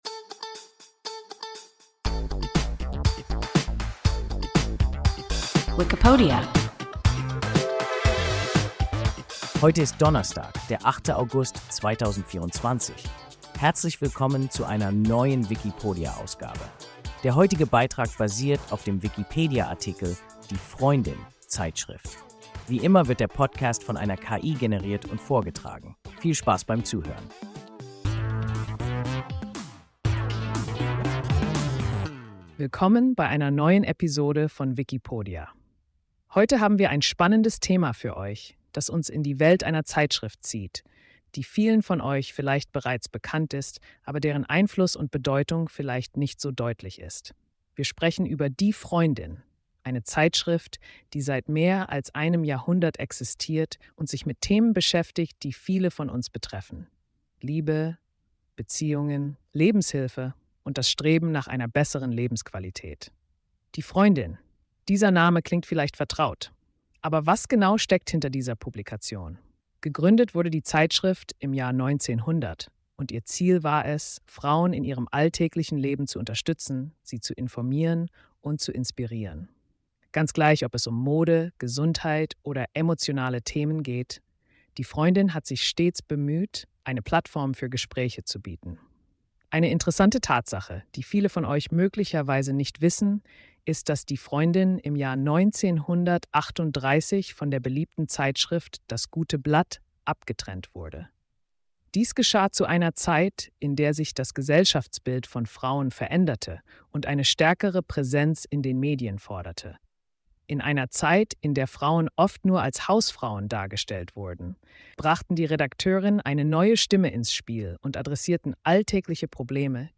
Die Freundin (Zeitschrift) – WIKIPODIA – ein KI Podcast